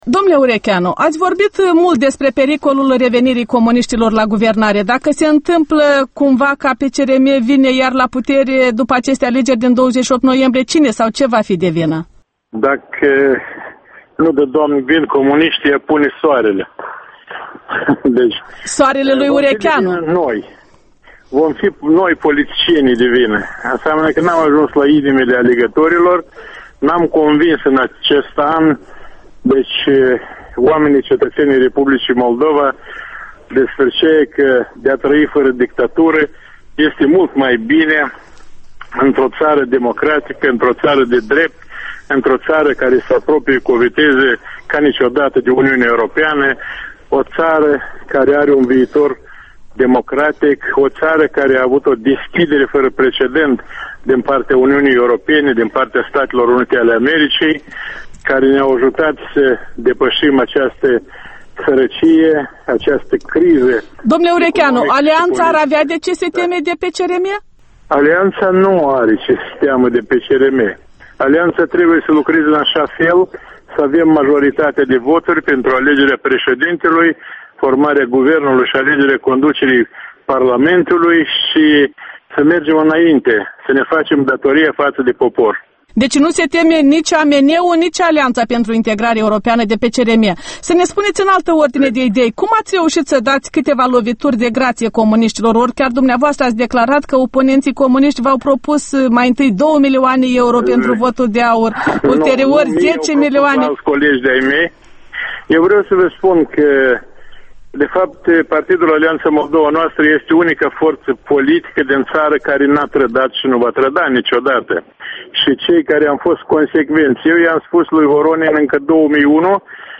Interviul Electorala 2010: cu Serafim Urechean